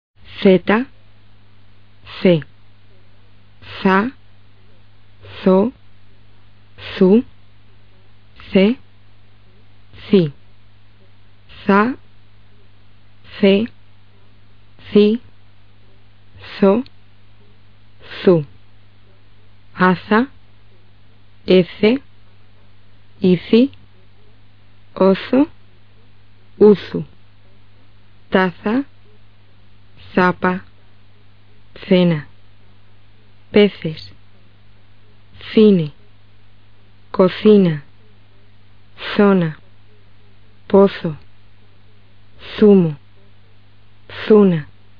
Z的名称是zetaC的名称是ce。两个字母的音标都是【θ】。【θ】是舌尖齿间擦清辅音。CEIZA, OU之前都发这个音。发音时，舌尖从上下门齿之间伸出，让气流通过，声带不振动。